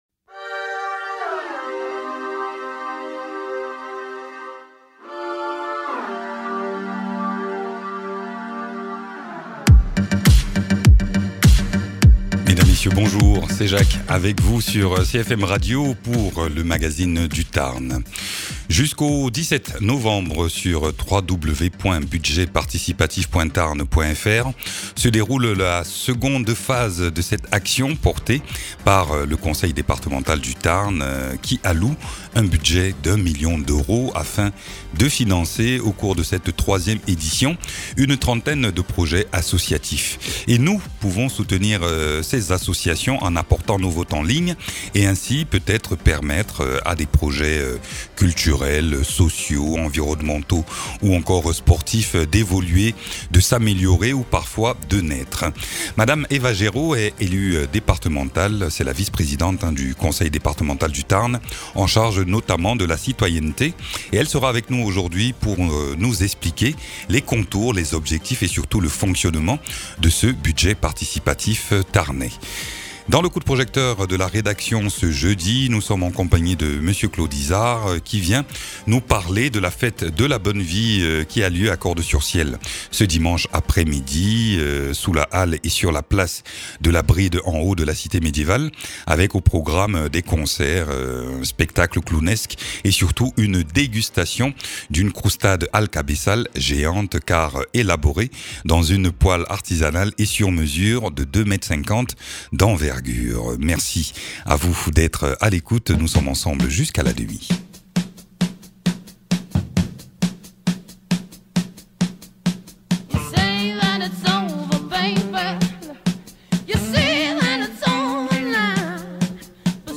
Invité(s) : Eva Géraud, Vice-Présidente du Conseil Départemental du Tarn en charge de la citoyenneté